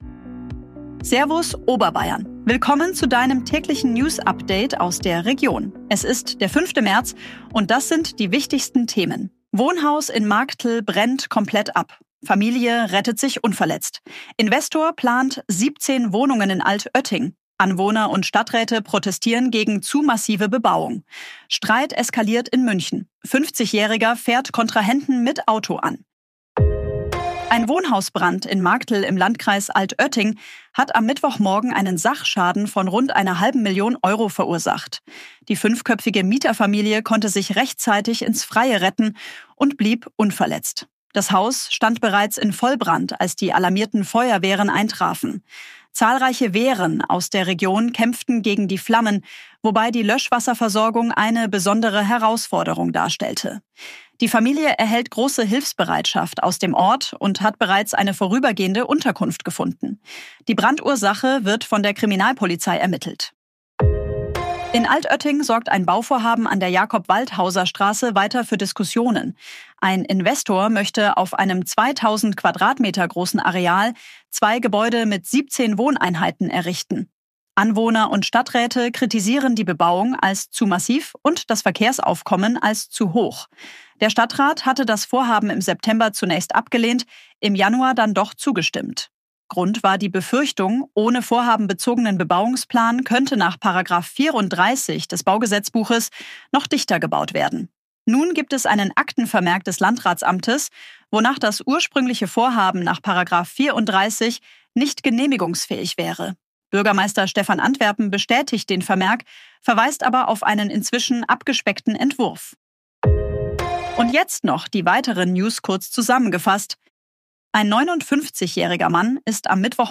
Tägliche Nachrichten aus deiner Region
Update wurde mit Unterstützung künstlicher Intelligenz auf Basis